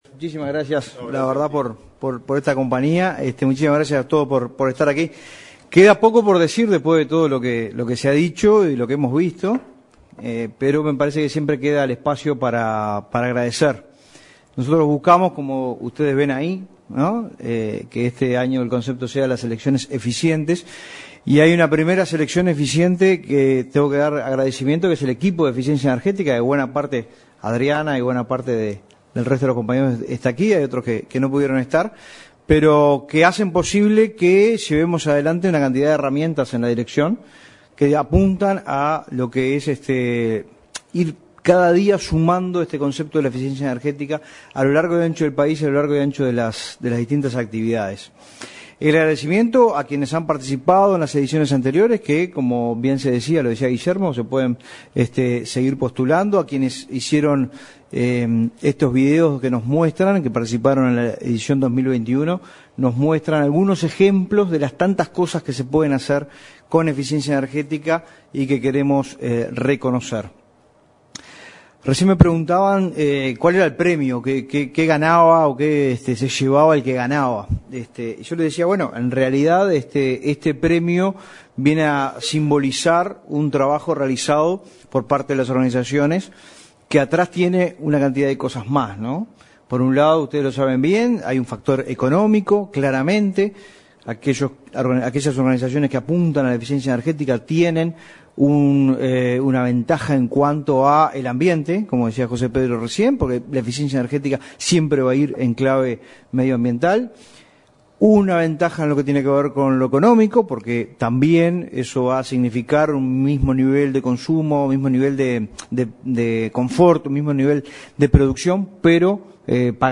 Palabras del director nacional de Energía, Fitzgerald Cantero
En el lanzamiento del Premio Nacional de Eficiencia Energética 2022, se expresó el director nacional de Energía, Fitzgerald Cantero.